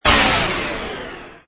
tractor_interrupted.wav